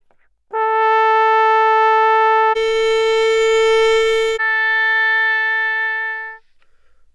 Obwohl die Tonhöhe bei allen Instrumenten bei 440 Hz liegt, sind noch klare klangliche Unterschiede wahrnehmbar
Horn_Geige_Oboe_Vergleich.wav